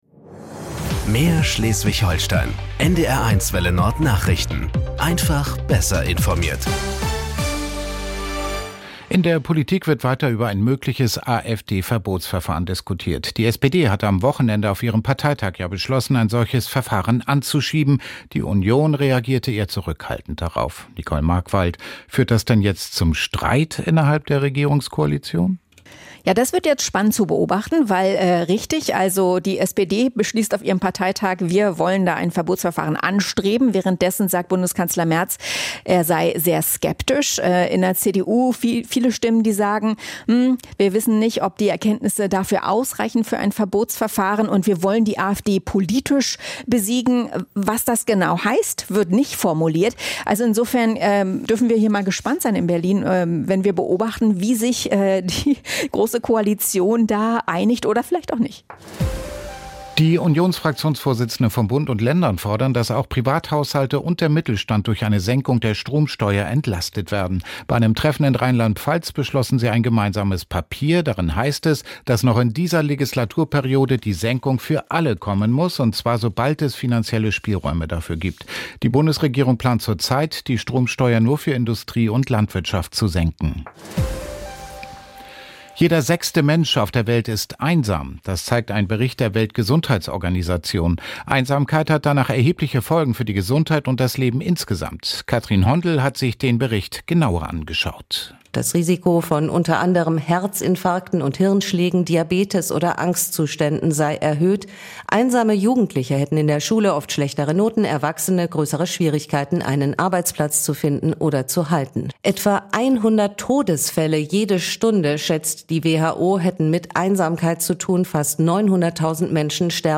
Nachrichten 18:00 Uhr - 30.06.2025